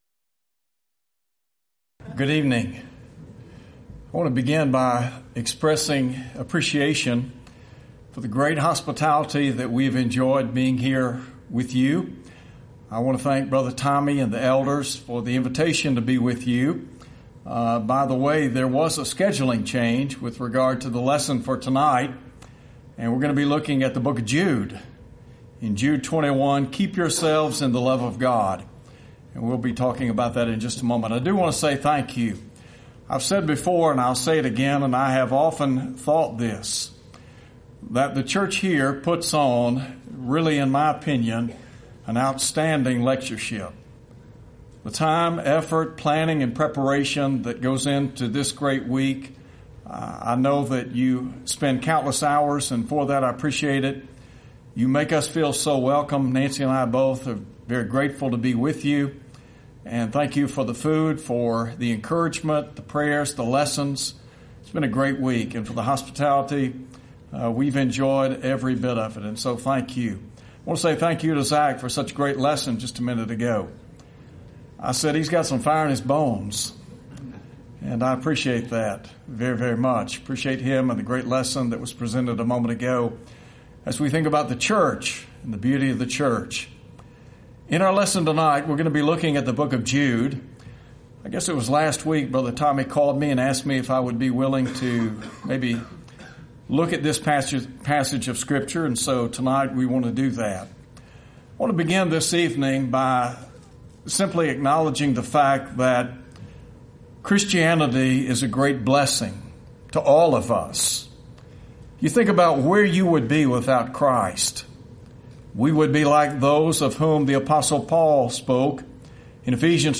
Event: 26th Annual Lubbock Lectures
lecture